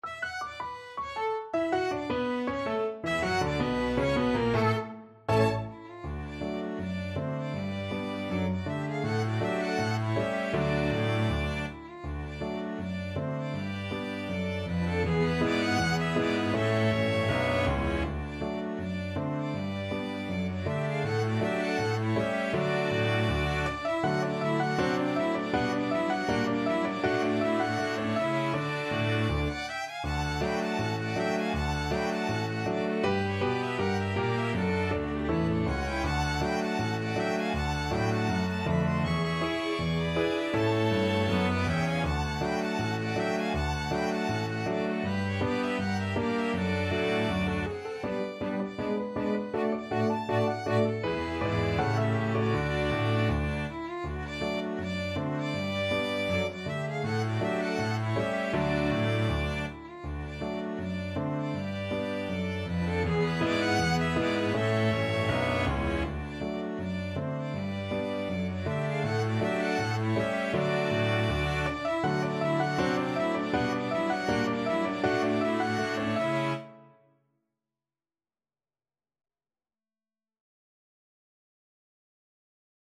Violin
Viola
Cello
Double Bass
Piano
"The Entertainer" is sub-titled "A rag time two step", which was a form of dance popular until about 1911, and a style which was common among rags written at the time.
4/4 (View more 4/4 Music)
D major (Sounding Pitch) (View more D major Music for Flexible Ensemble and Piano - 5 Players and Piano )
=250 Presto (View more music marked Presto)
Jazz (View more Jazz Flexible Ensemble and Piano - 5 Players and Piano Music)